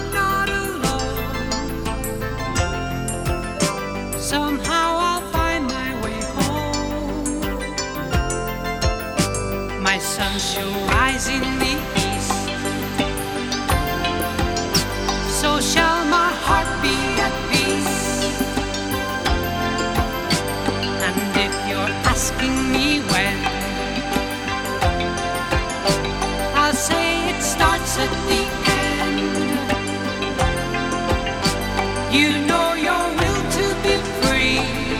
Жанр: Нью-эйдж